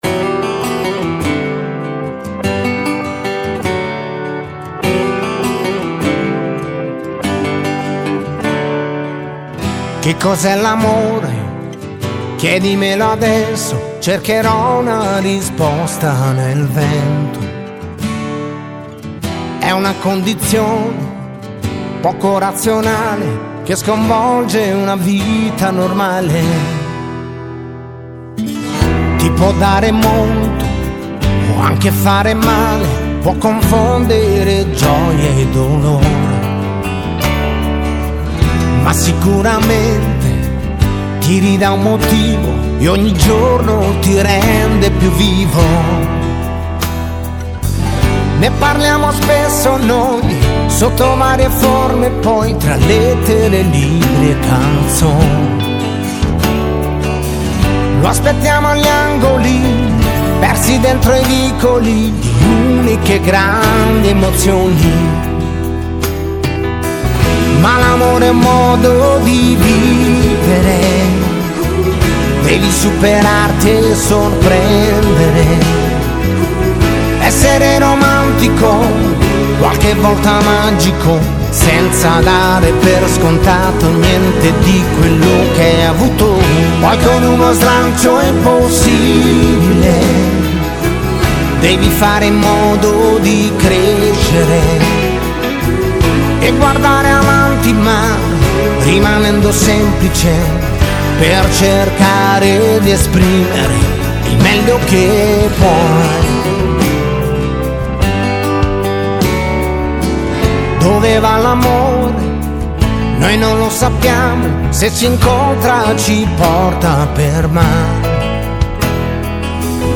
Pop, Pop Rock